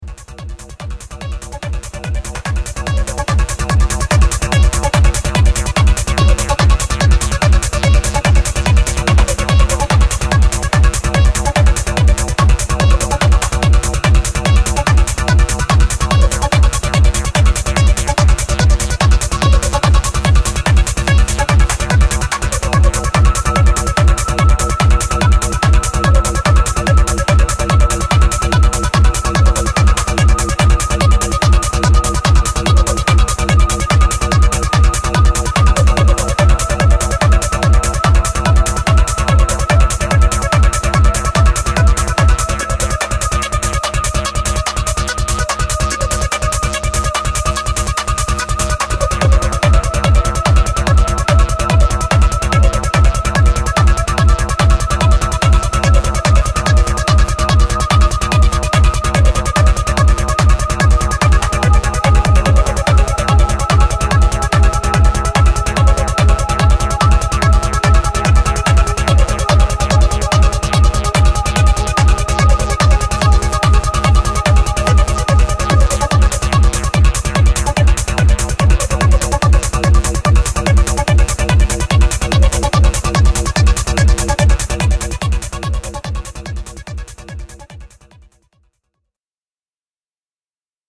driving rhythms and dark trance leads